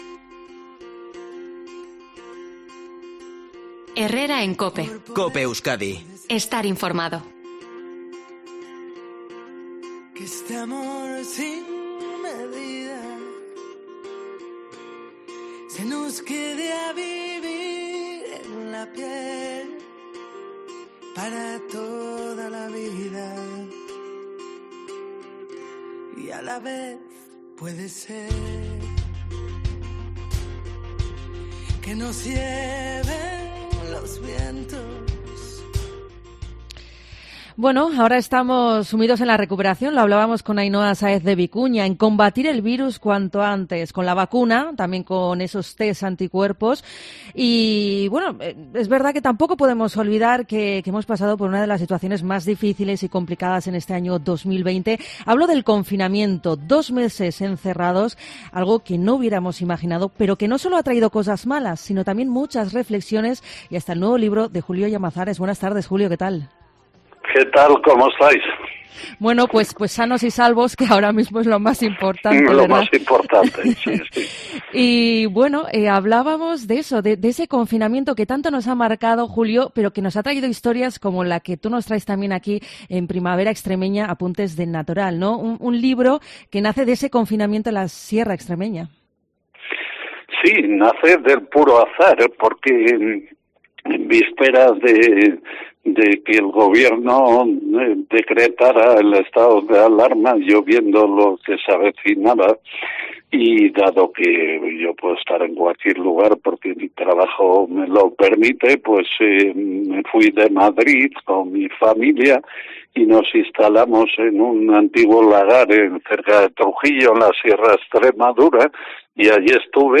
Entrevista a Julio Llamazares